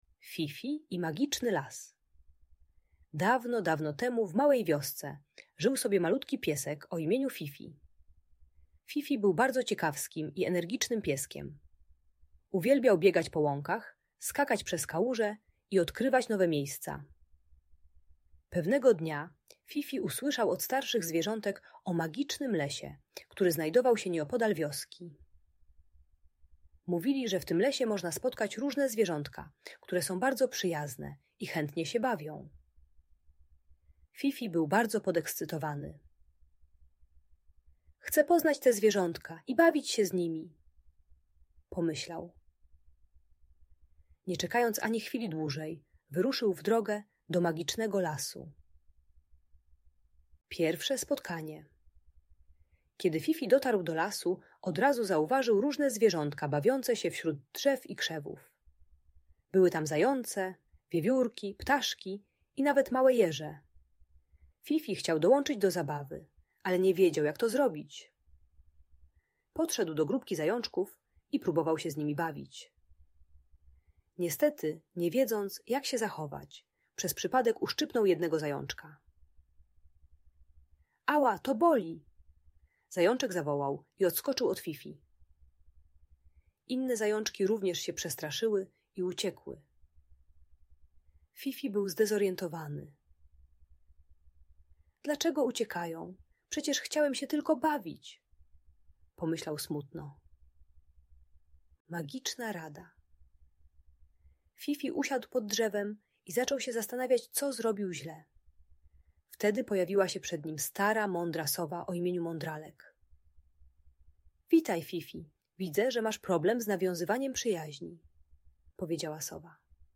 Fifi i Magiczny Las - story o przyjaźni i zabawie - Audiobajka